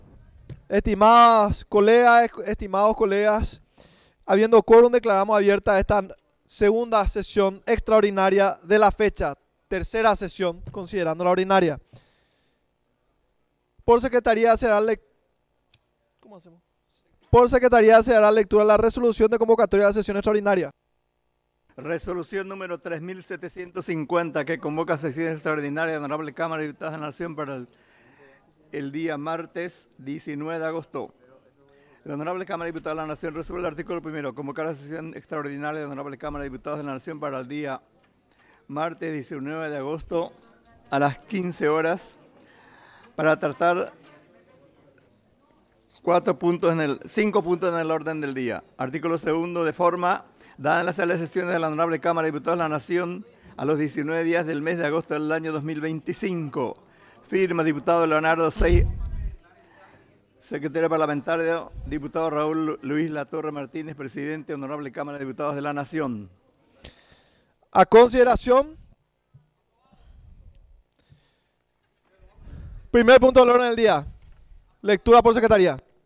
Sesión Extraordinaria – Segunda Sesión, 19 de agosto de 2025